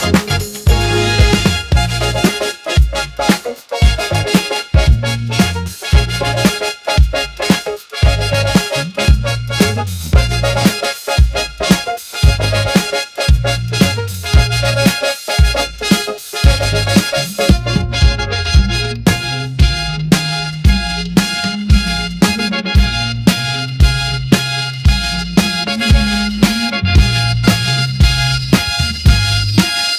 music-generation text-to-music